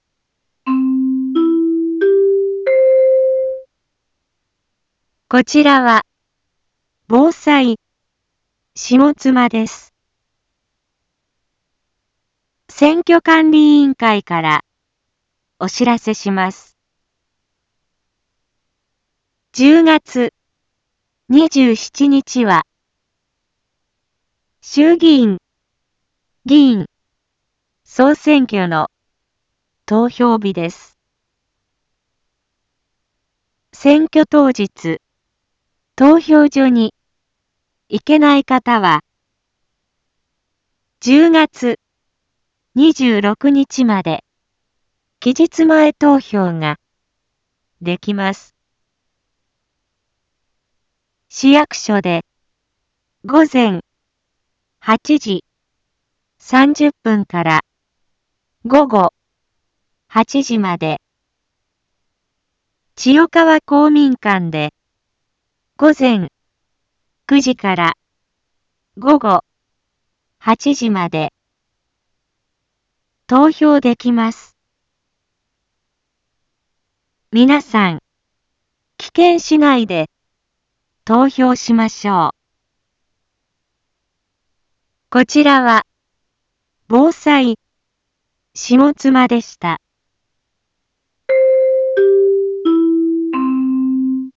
一般放送情報
Back Home 一般放送情報 音声放送 再生 一般放送情報 登録日時：2024-10-17 13:01:39 タイトル：衆議院議員総選挙の啓発（期日前投票期間） インフォメーション：こちらは、ぼうさいしもつまです。 選挙管理委員会からお知らせします。